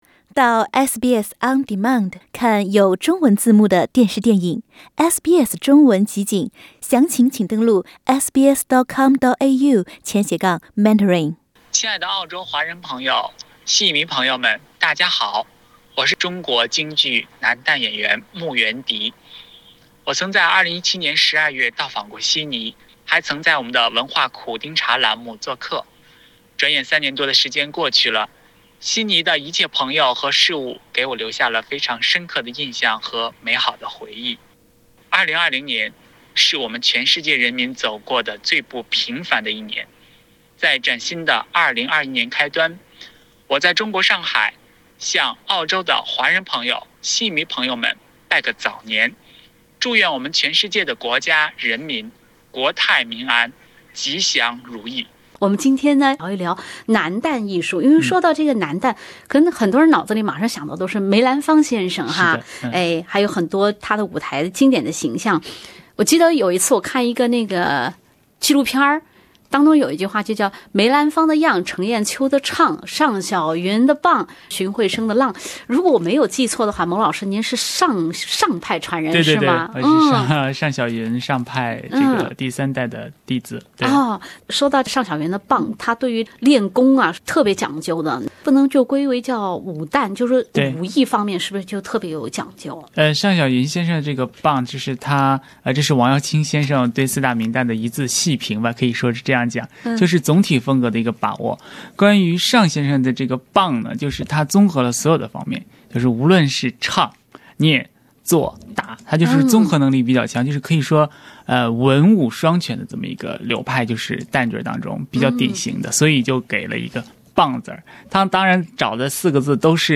牛年到来之际，中国男旦领军人物牟元笛先生通过文化苦丁茶节目向澳洲戏迷朋友送上自己真挚的祝福。（点击封面图片，收听完整对话）